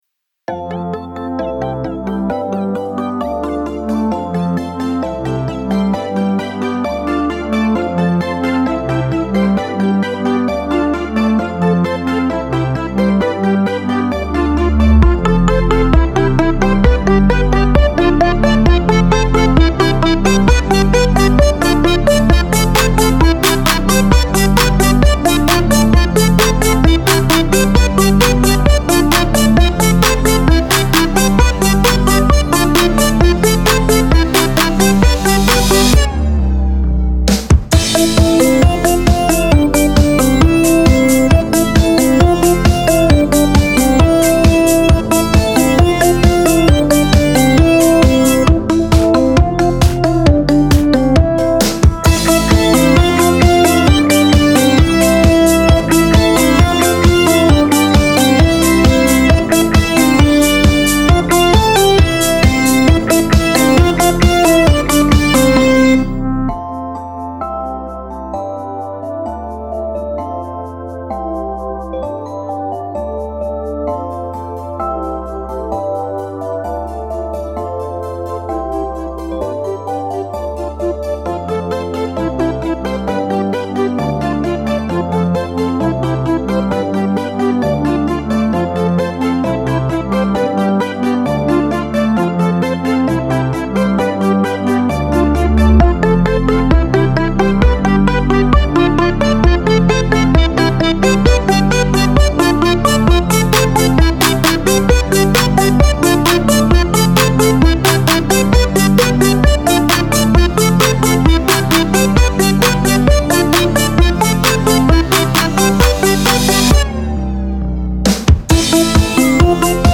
EDM